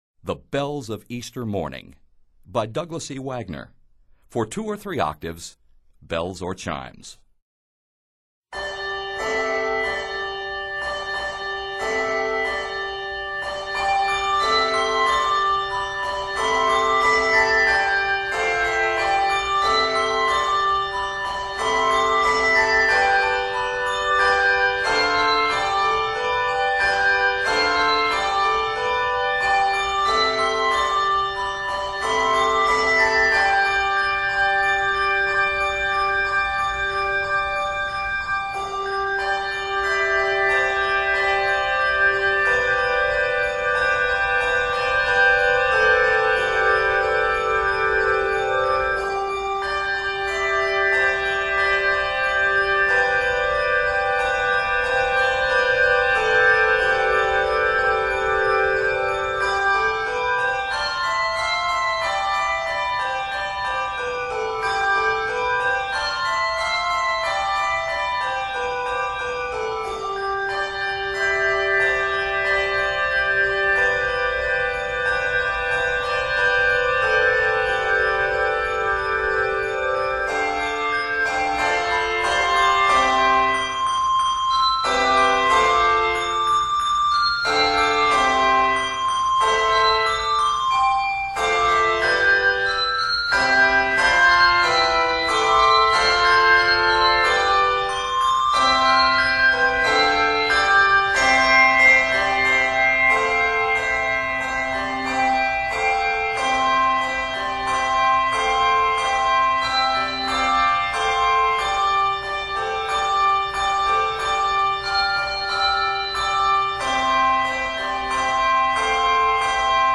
scored in a major key
scored in G Major and C Major